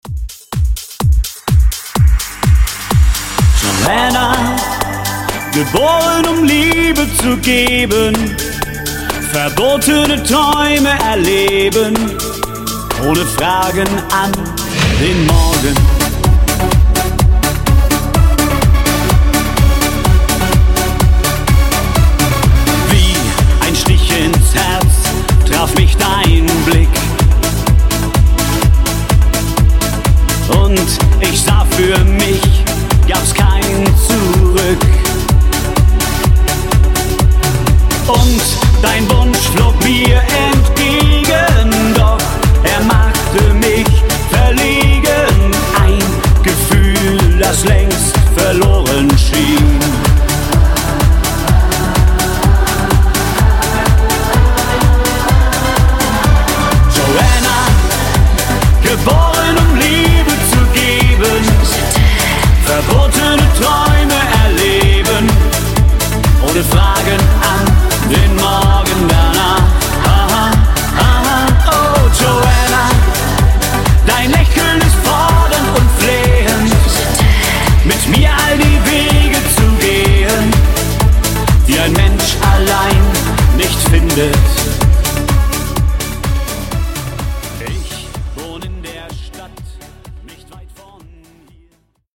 Genres: 90's , RE-DRUM
Clean BPM: 115 Time